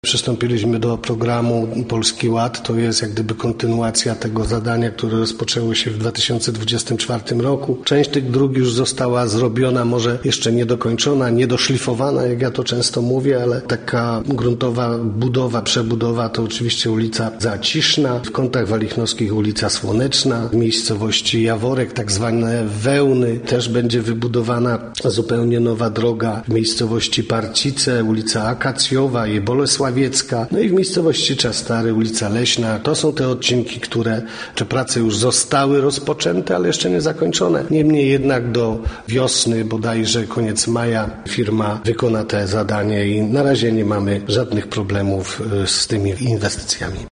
– mówił wójt gminy Czastary, Dariusz Rejman.